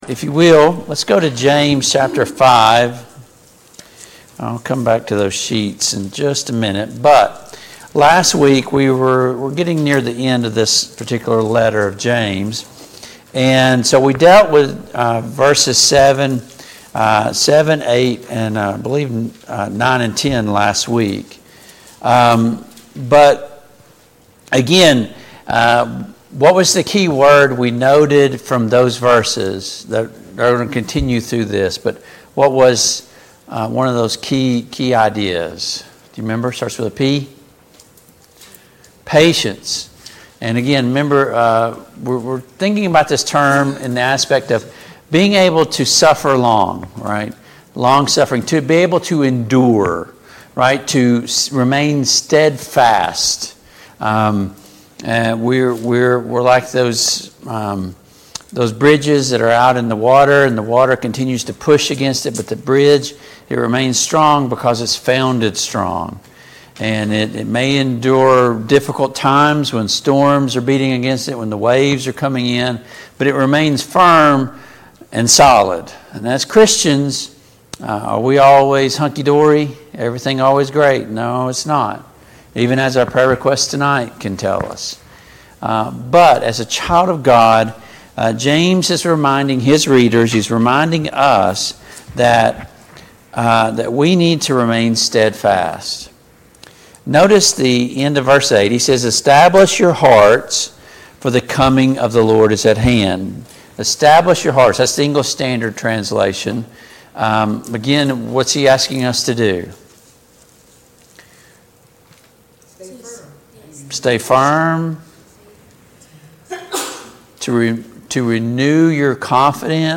Service Type: Family Bible Hour